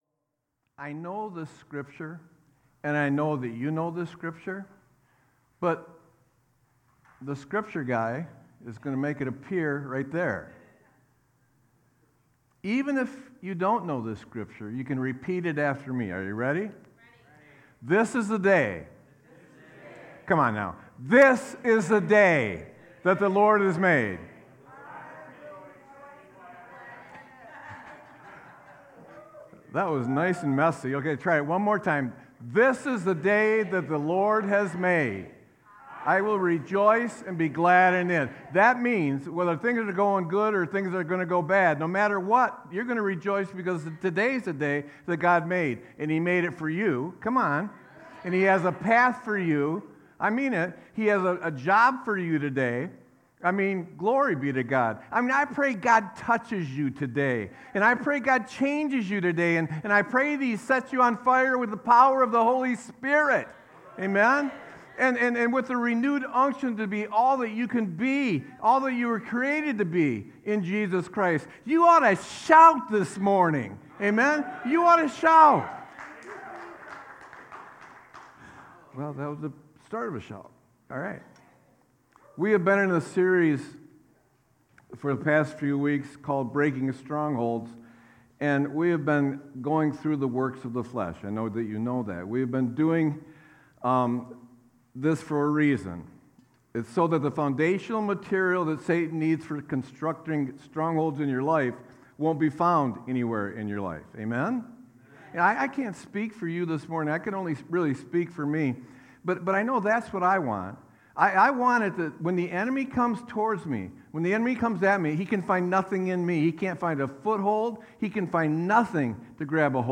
Sermon-5-05-24.mp3